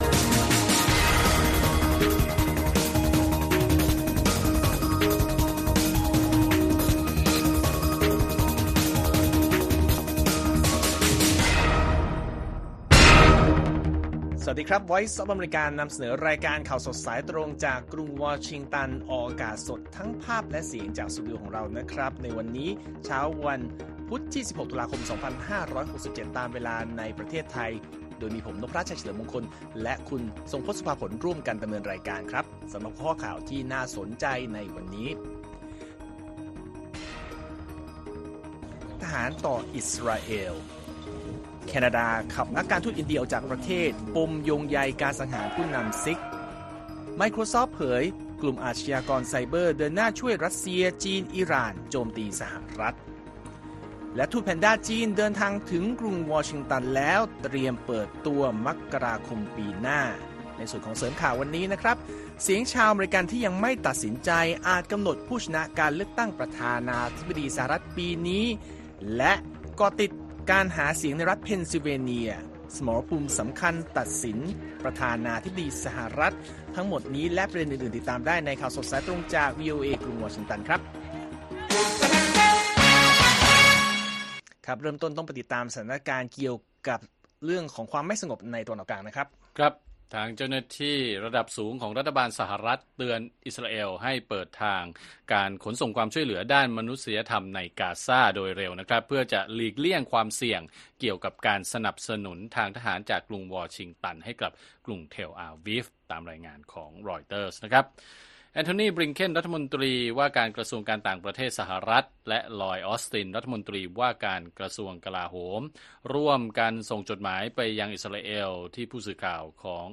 ข่าวสดสายตรงจากวีโอเอ ไทย พุธ ที่ 16 ตุลาคม พ.ศ. 2567